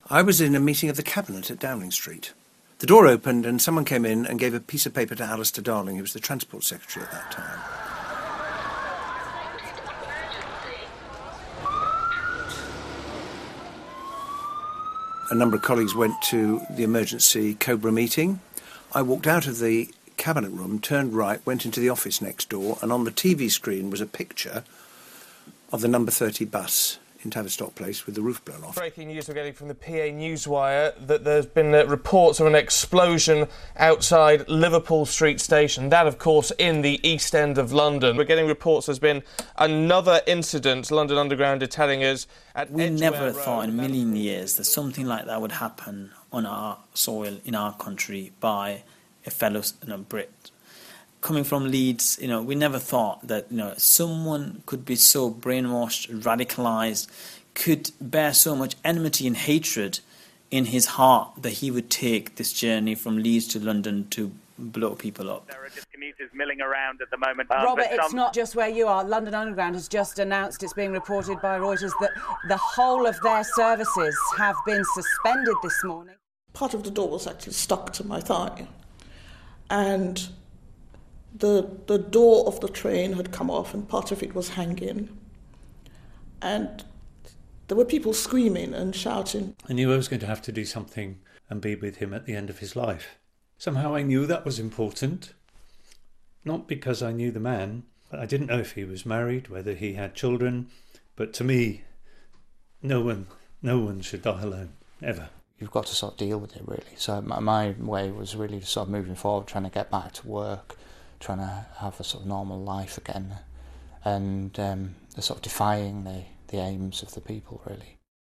Community leaders and activists in Leeds look back to the moment they heard the news.